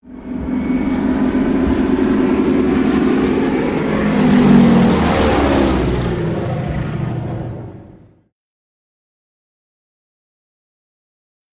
Japanese Zero|Bys
Airplane Japanese Zero By Left To Right Medium Speed Close Perspective